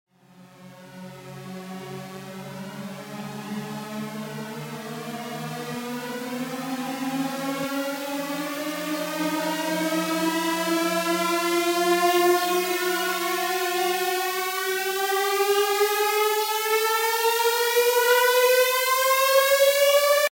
Tag: 95 bpm Techno Loops Fx Loops 3.41 MB wav Key : Unknown